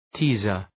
Προφορά
{‘ti:zər}